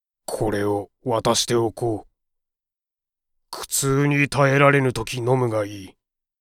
パロディ系ボイス素材　4